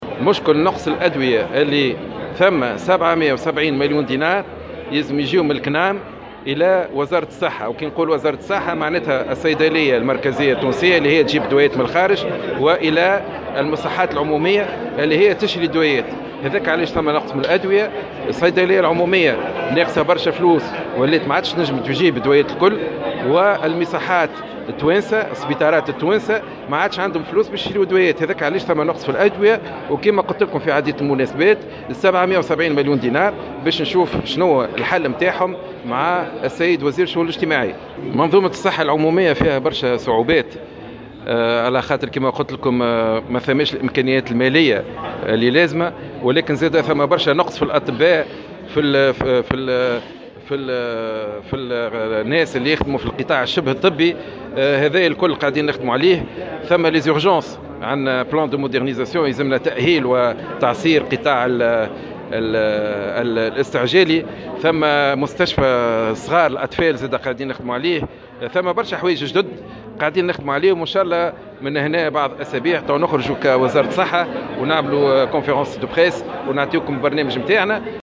Slim Chaker, ministre de la santé publique a indiqué ce vendredi 29 septembre 2017, dans une déclaration accordée à Jawhara FM, que le ministère et la pharmacie centrale sont aujourd’hui dans l’incapacité d’acheter plusieurs types de médicaments.